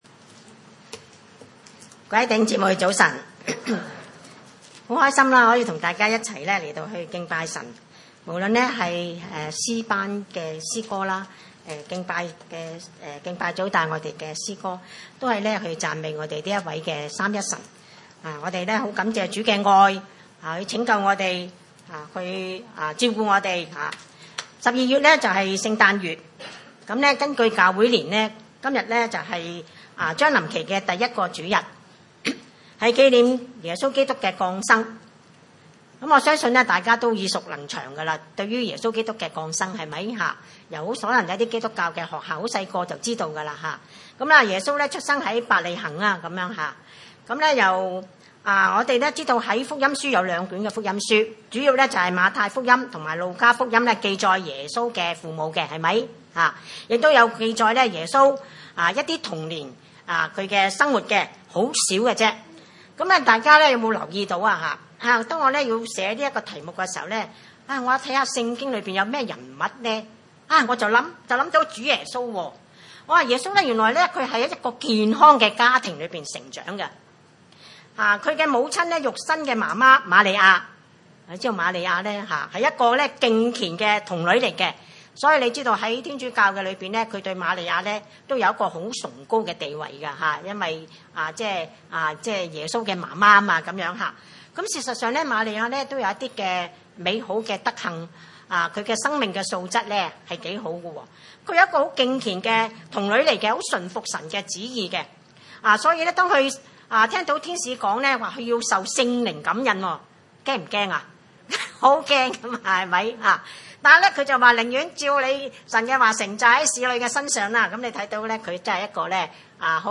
弗六 1-4 崇拜類別: 主日午堂崇拜 6:1 你 們 作 兒 女 的 、 要 在 主 裡 聽 從 父 母 、 這 是 理 所 當 然 的 。